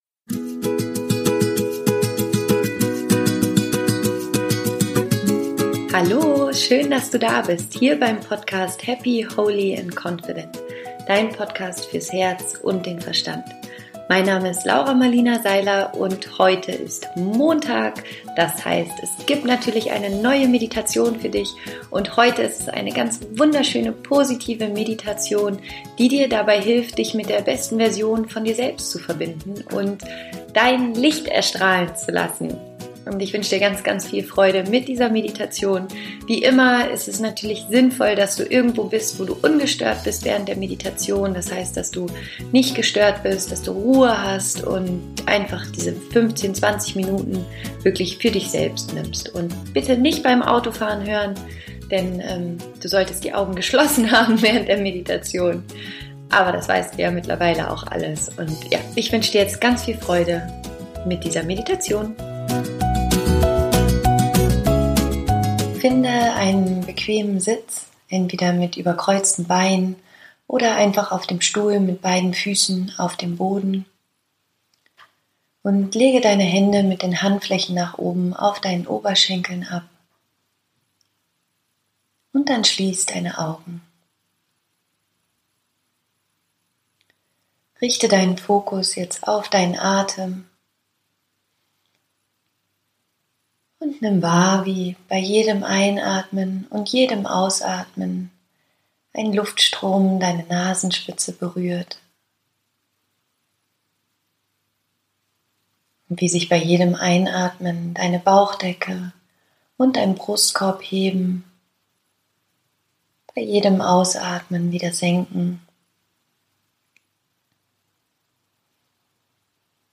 Meditation: Beste Version von dir selbst